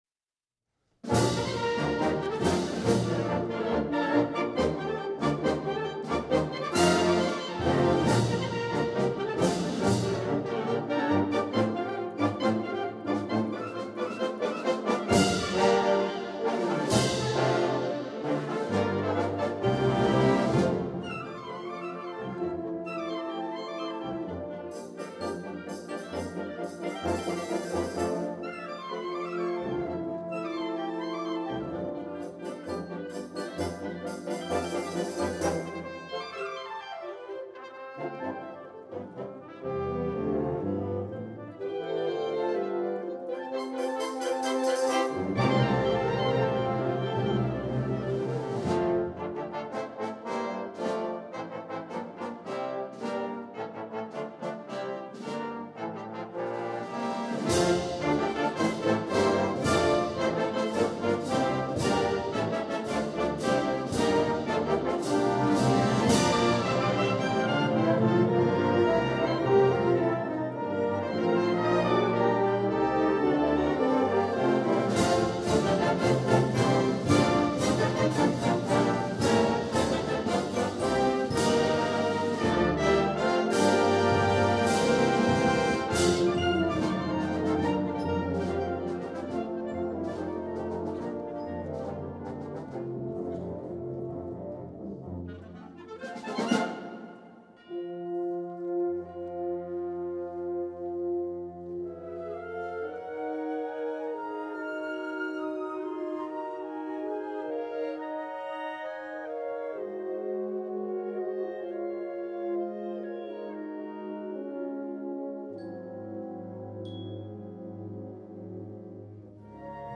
Besetzung: Sinfonieorchester